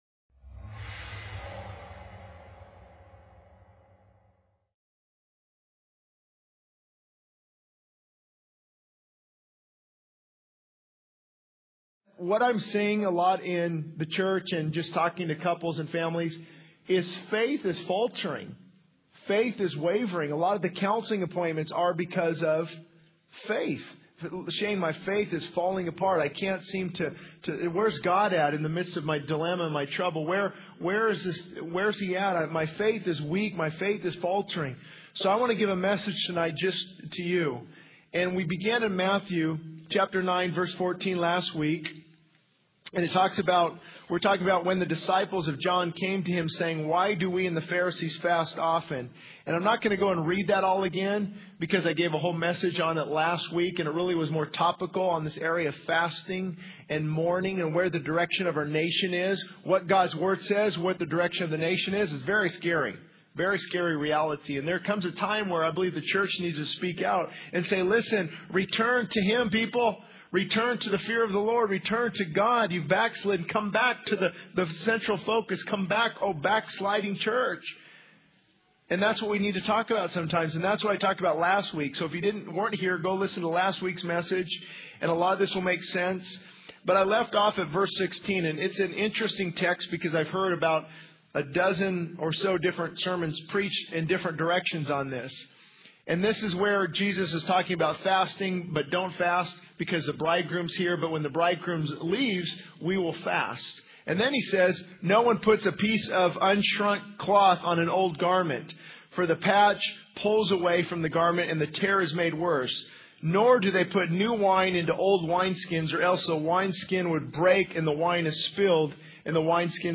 This sermon emphasizes the importance of faith and trust in God, highlighting how faith can falter when worship, obedience, reinforcement of God's promises, and trust are lacking. It stresses the need for a fully surrendered life to build strong faith, drawing examples from biblical figures like Abraham, Sarah, and Noah. The message encourages diligent seeking of God, reinforcing promises, and trusting Him even in the midst of trials and tragedies.